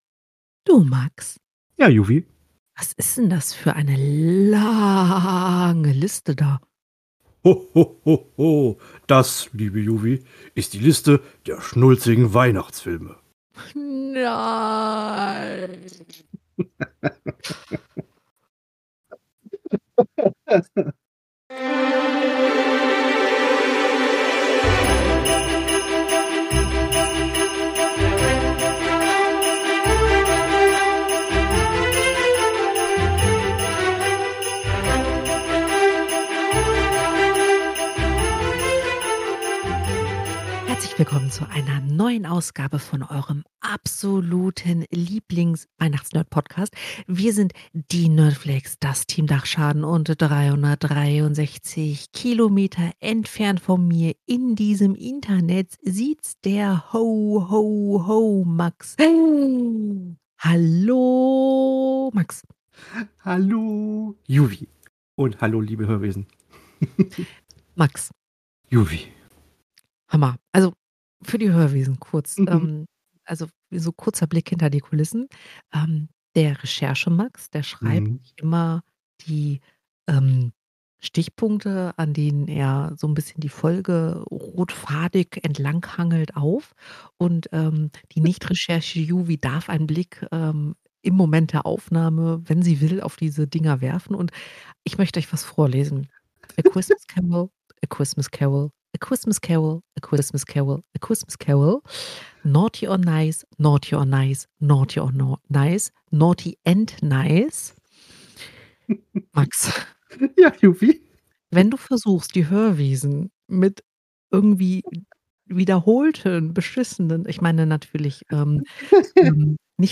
In der heutigen - ungeschnittenen - Episode meckern die Nerds einfach mal eine halbe Stunde über unsägliche Weihnachtsfilme.